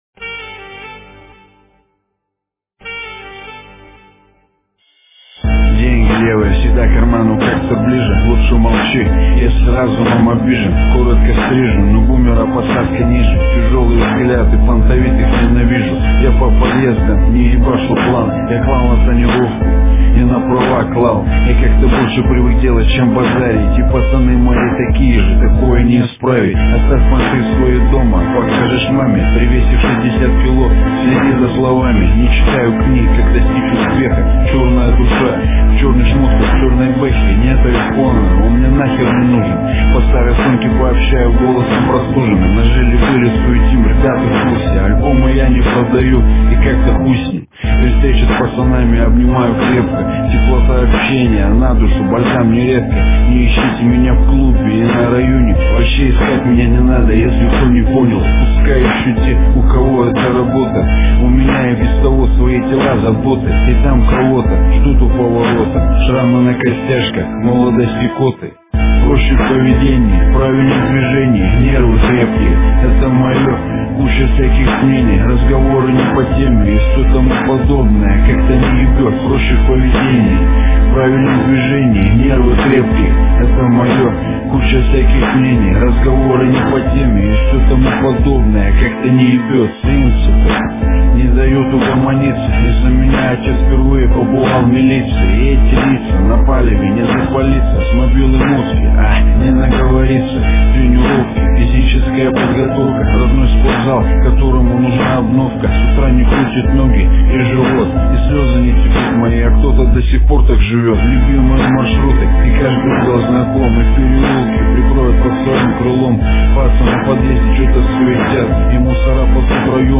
Жанр:Рэп